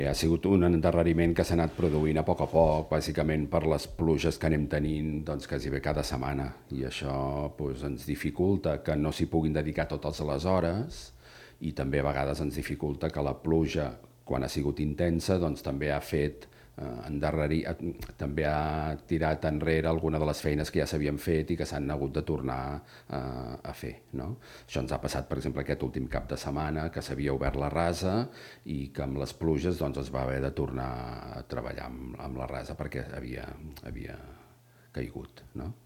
El regidor d'Urbanisme, Xavier Collet, atribueix l'endarreriment a la pluja i assegura que s'està treballant per recuperar el temps perdut.